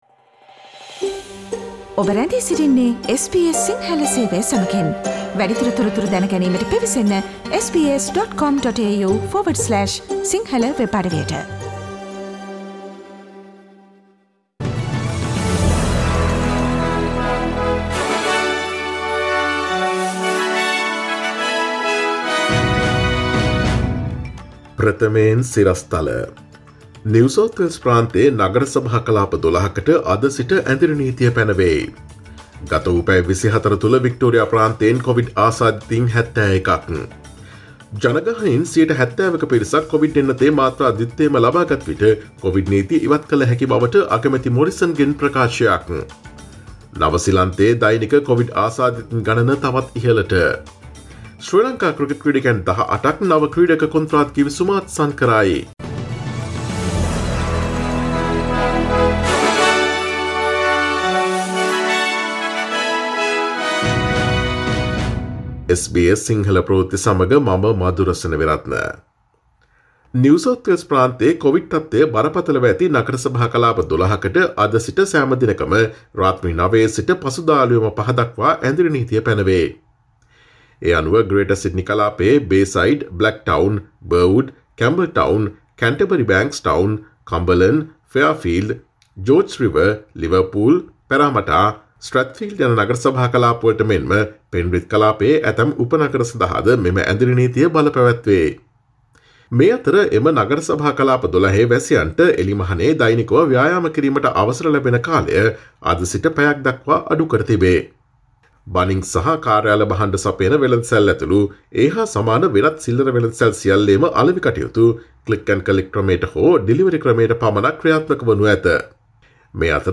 අගෝස්තු 23දා SBS සිංහල ප්‍රවෘත්ති: ඔස්ට්‍රේලියාවේ කොවිඩ් නීති ඉවත් කළ හැකි ඉලක්කය ගැන අගමැති මොරිසන්ගෙන් ප්‍රකාශයක්
ඔස්ට්‍රේලියාවේ නවතම පුවත් මෙන්ම විදෙස් පුවත් සහ ක්‍රීඩා පුවත් රැගත් SBS සිංහල සේවයේ 2021 අගෝස්තු මස 23 වන දා සඳුදා වැඩසටහනේ ප්‍රවෘත්ති ප්‍රකාශයට සවන් දීමට ඉහත ඡායාරූපය මත ඇති speaker සලකුණ මත click කරන්න.